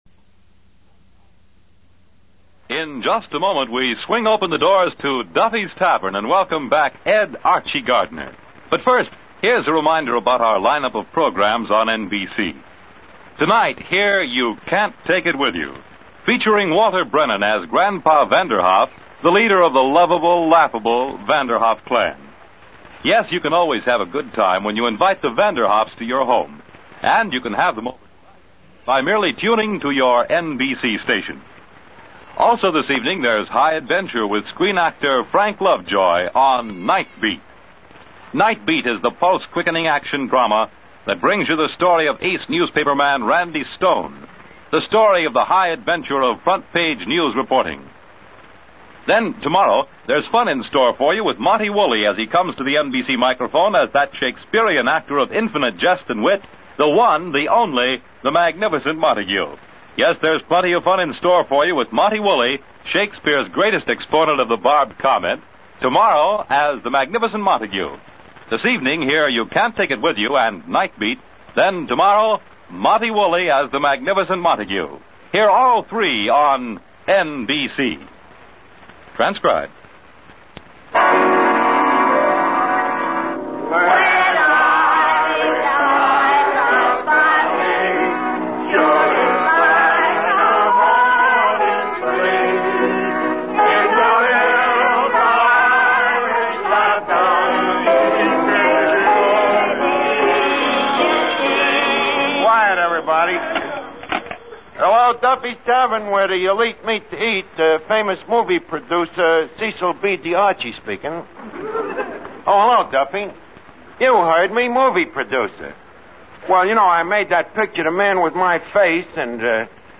Duffy's Tavern Radio Program, Starring Ed Gardner